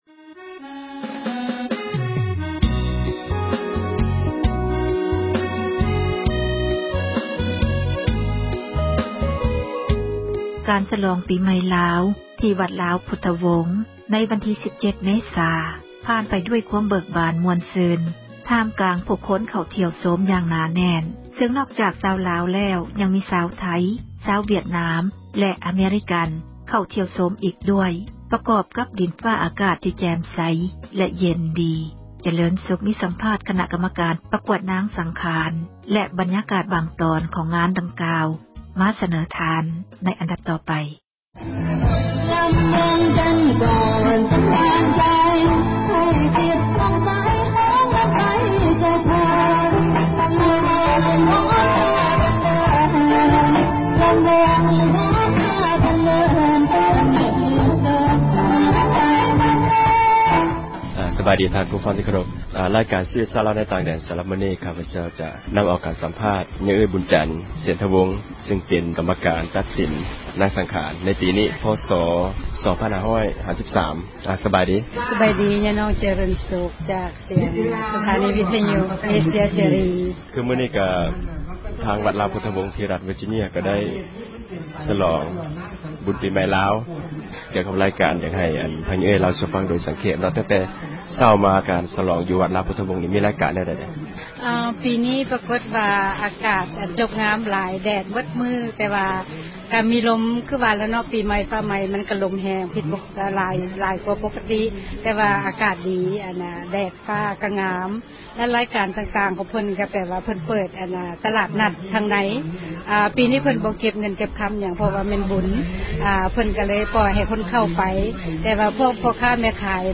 ມີສັມພາດ ຄະນະກັມມະການ ປະກວດນາງສັງຂານ ແລະ ບັນຍາກາດ ບາງຕອນ ຂອງງານ ດັ່ງກ່າວ
F-Ms-2553 ພິທີປະກວດ ນາງສັງຂານປະຈໍາປີ ພ.ສ 2553 ໃນງານເທສການ ປີໃໝ່ລາວ ທີ່ວັດລາວພຸທວົງ ທີ່ຣັດເວີຈີເນັຽ ສະຫະຣັດ ອະເມຣິກາ.